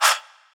Rapid FX - [murda].wav